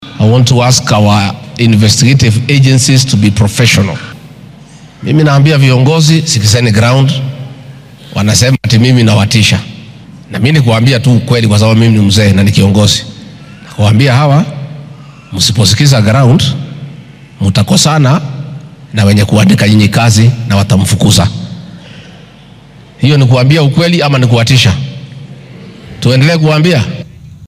Xilli uu ku sugnaa xaafadda Kayole ayuu isagoo arrimahan ka hadlaya yiri.